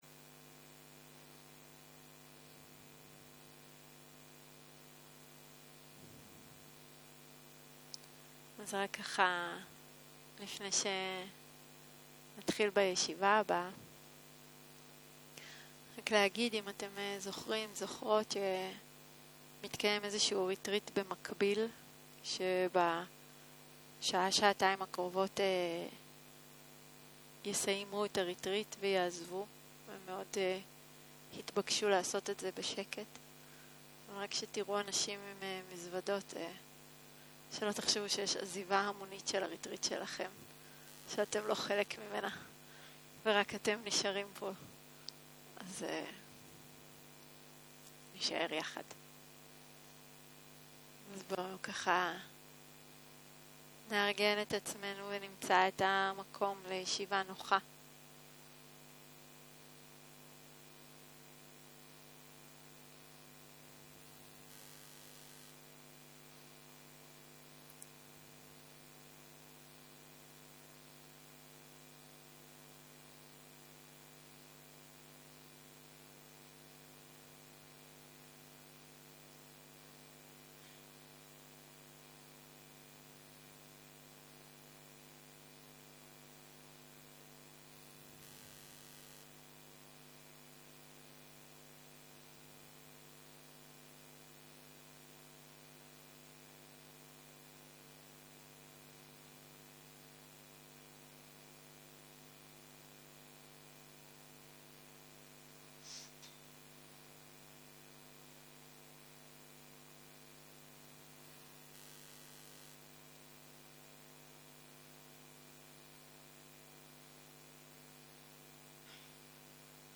יום 3 - צהרים - מדיטציה מונחית - עבודה עם כאב - הקלטה 6 | Tovana
Dharma type: Guided meditation שפת ההקלטה